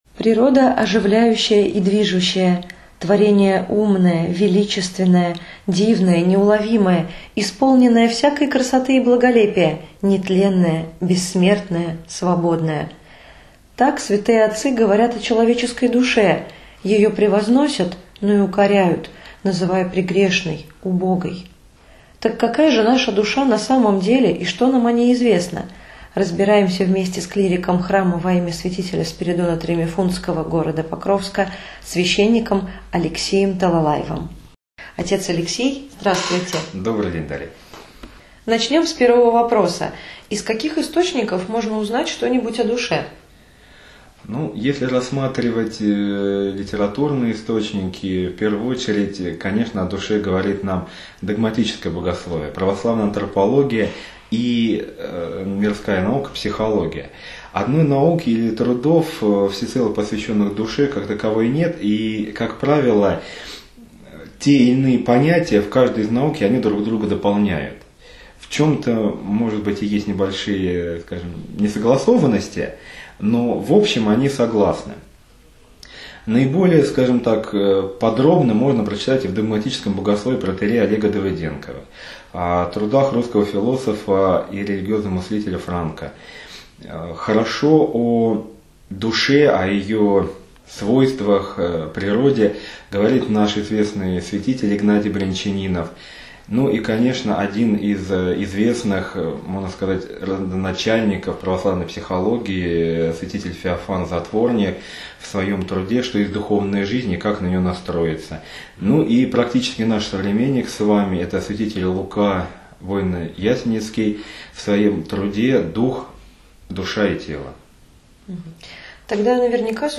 Беседа.-Часть-I.mp3